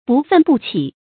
不愤不启 bù fèn bù qǐ
不愤不启发音